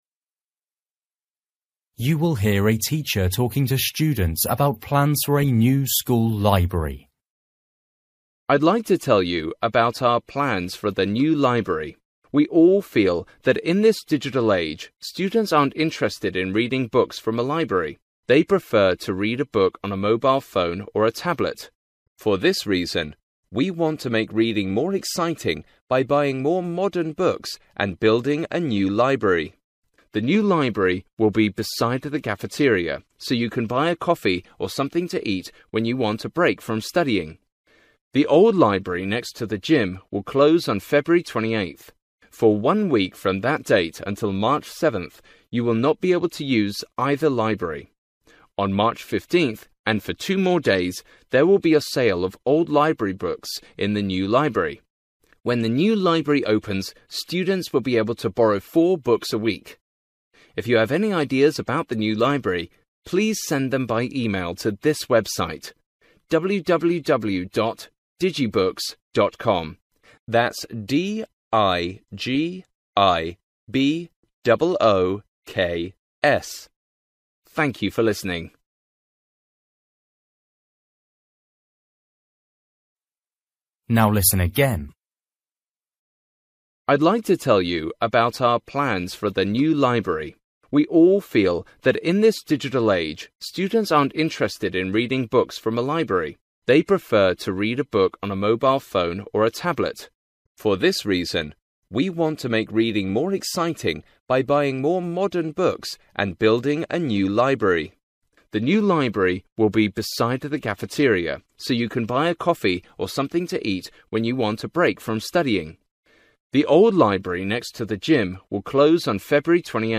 You will hear a teacher talking to students about plans for a new school library.